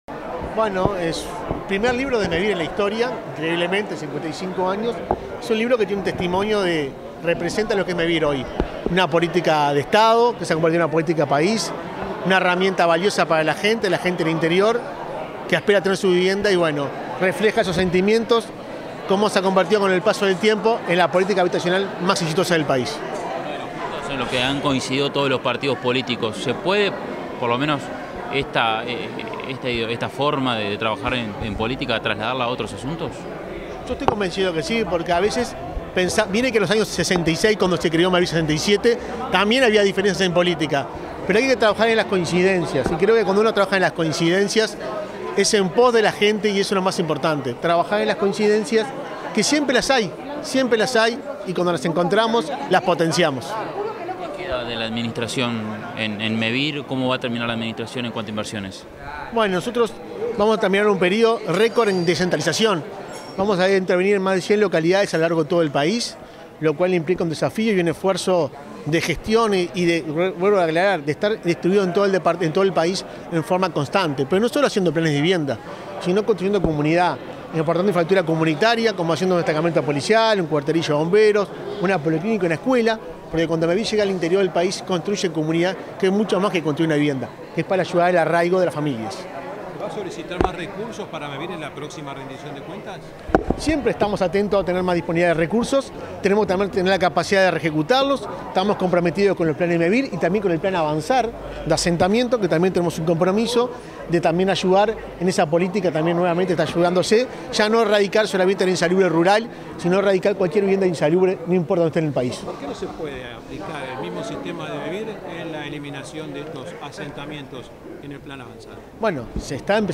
Tras la presentación de un libro, este 23 de mayo, el presidente de Mevir, Juan Pablo Delgado, realizó declaraciones a la prensa.